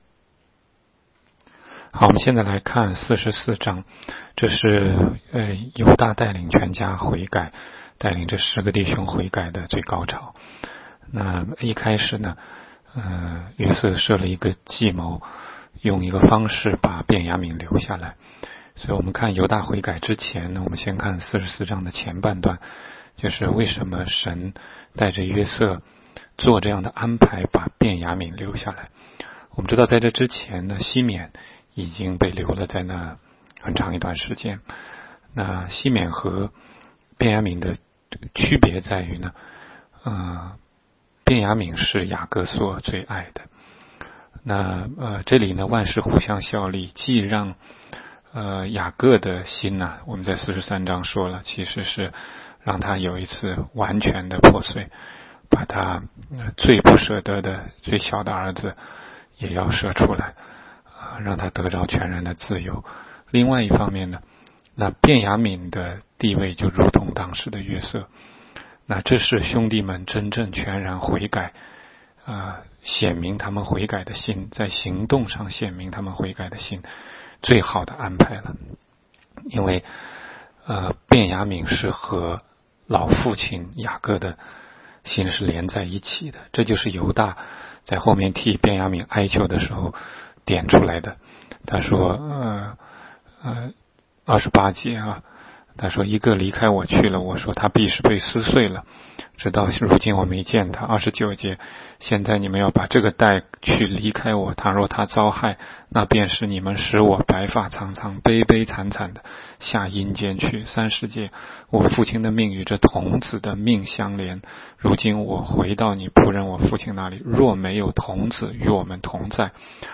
16街讲道录音 - 每日读经-《创世记》44章